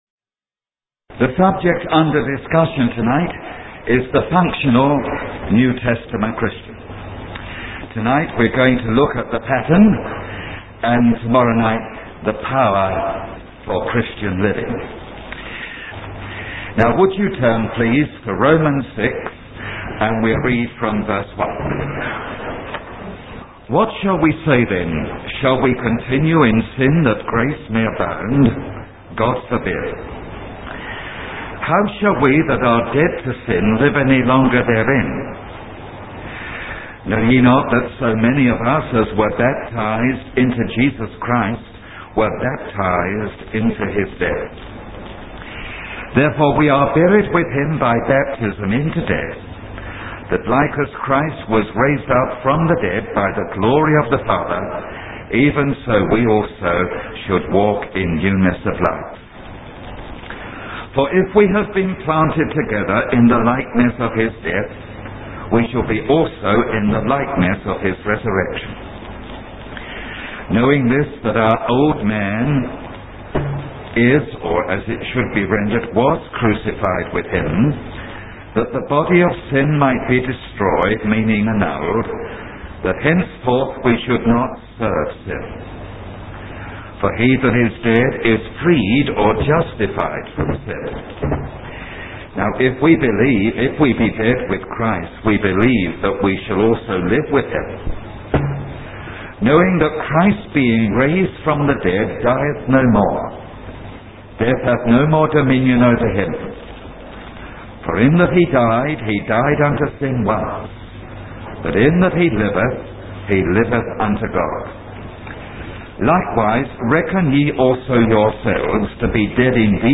The sermon highlights the importance of understanding one's position in Christ to live a victorious Christian life.